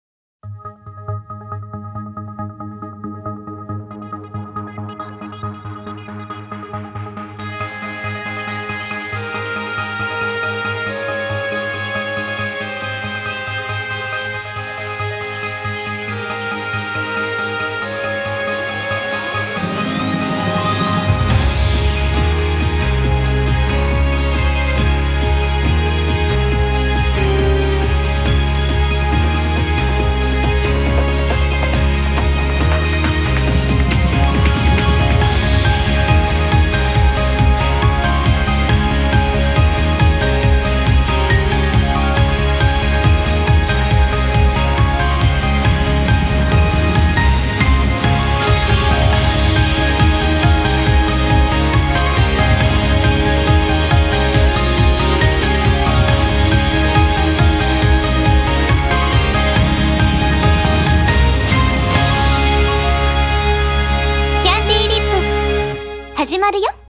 Country: Japan, Genre: J-Pop